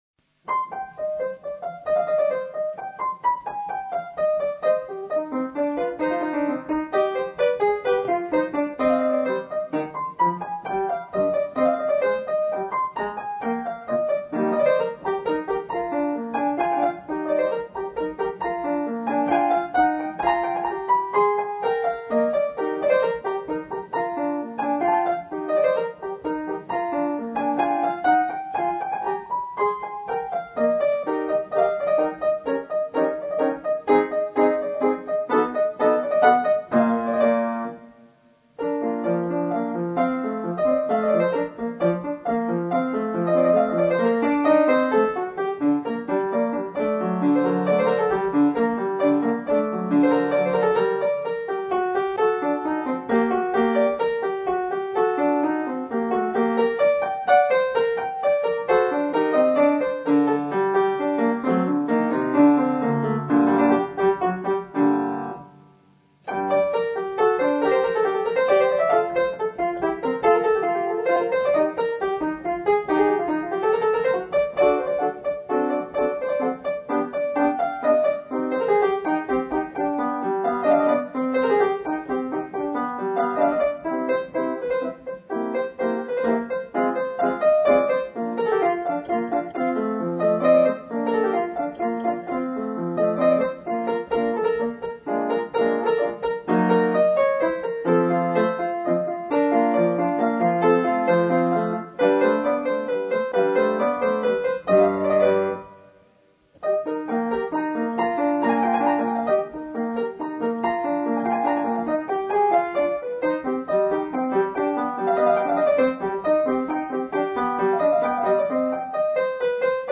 Piano Recital: CD 5